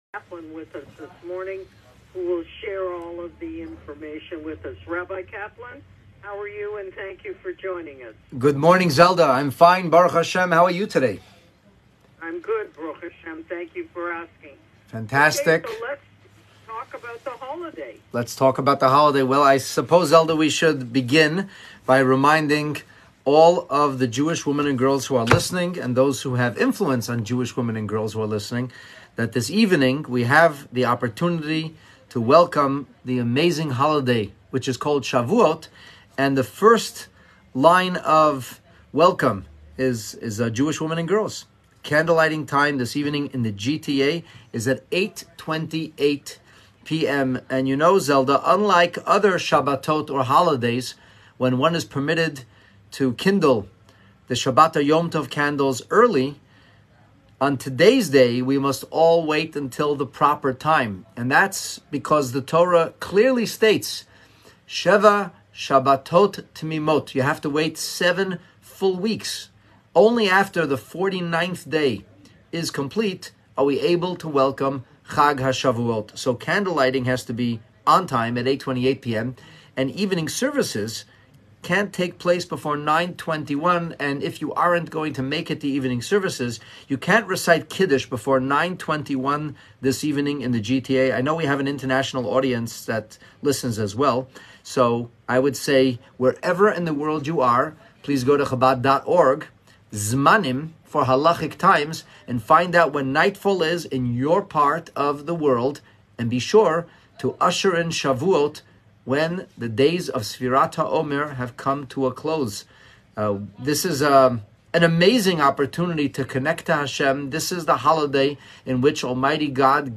Multicultural Radio in Toronto.